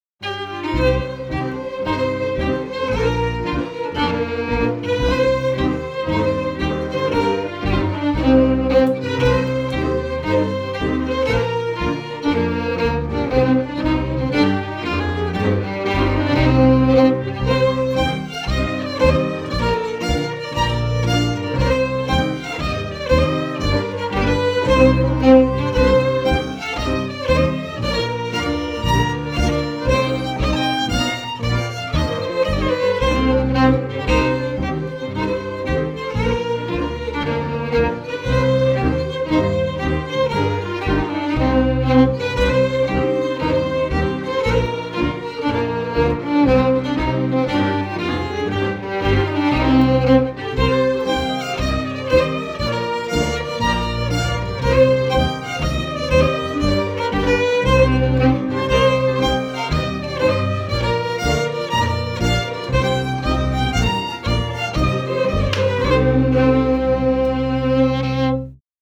as strathspey